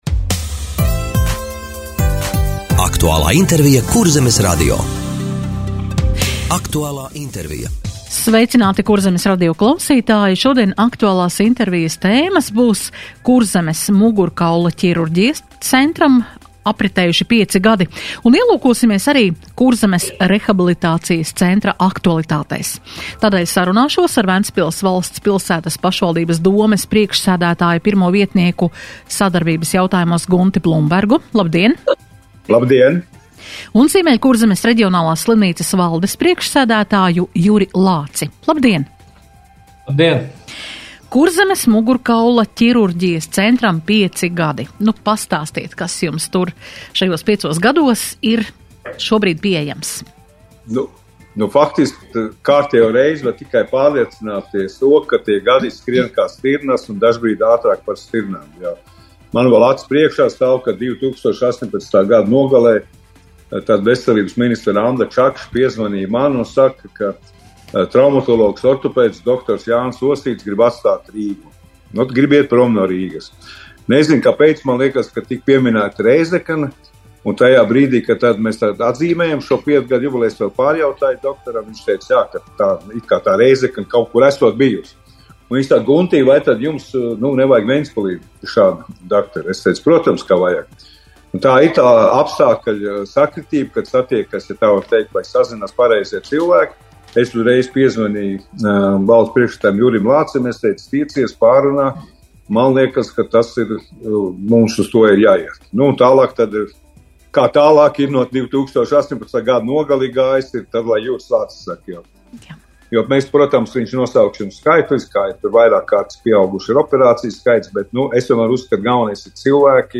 Radio saruna Ziemeļkurzemes reģionālās slimnīcas aktualitātes